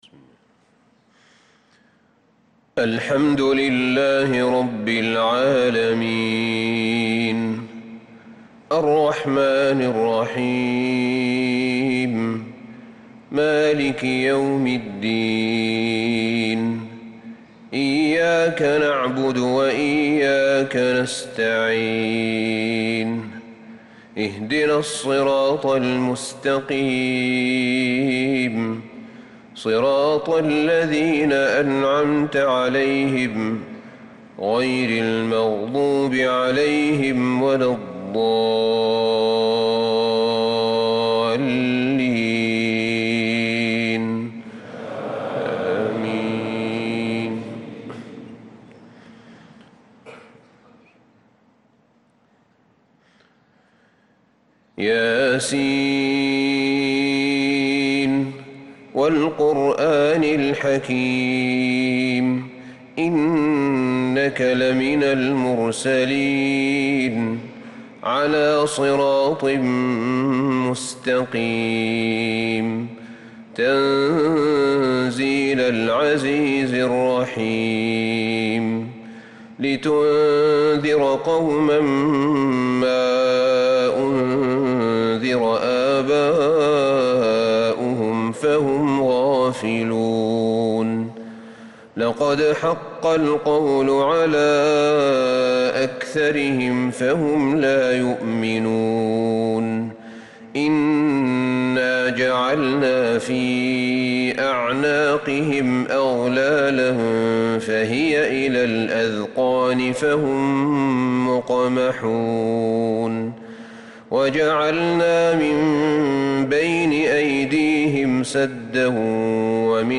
صلاة الفجر للقارئ أحمد بن طالب حميد 21 محرم 1446 هـ